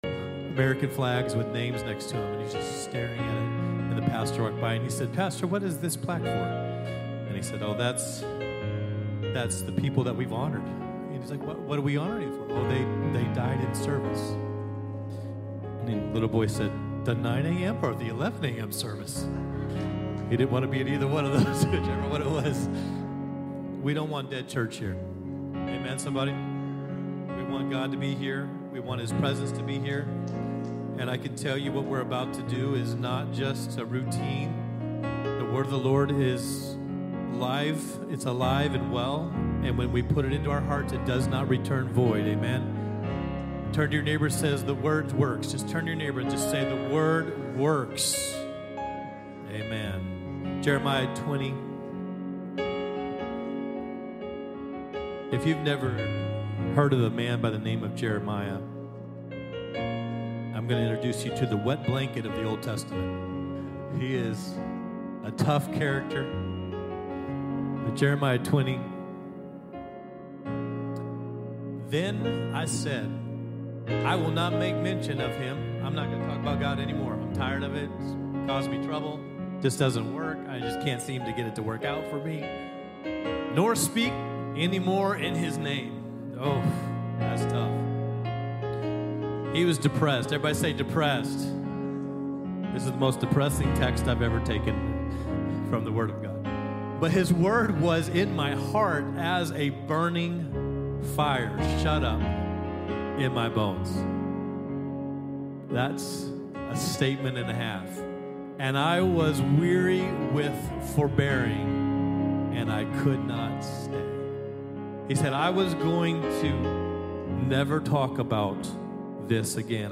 Welcome to the weekly podcast of LifeSpring Church. Listen to relevant, biblical teachings that inspire and change lives!